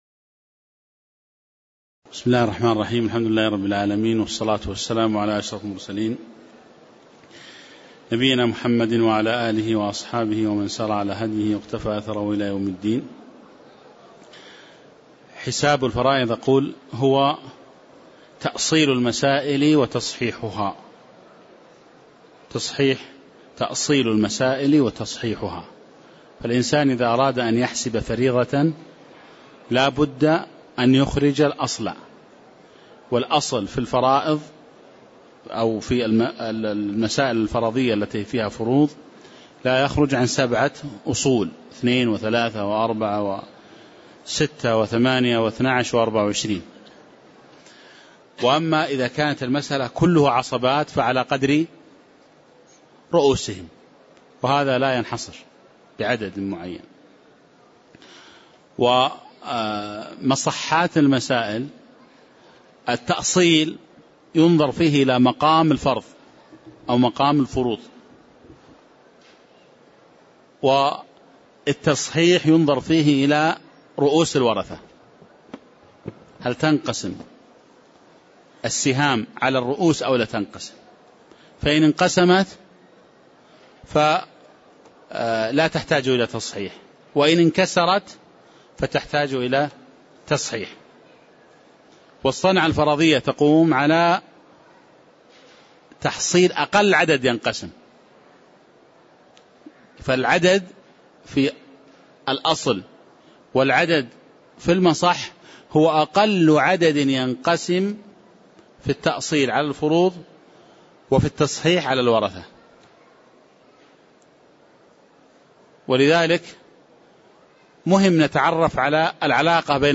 تاريخ النشر ٢٢ شوال ١٤٣٧ هـ المكان: المسجد النبوي الشيخ